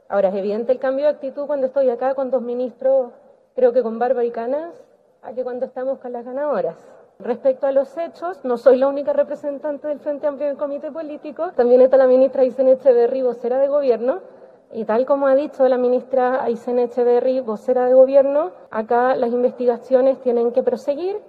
Posteriormente, ya en el punto de prensa, en el que participaron los ministros que asistieron a la actividad, la titular de La Mujer nuevamente fue requerida por el tema.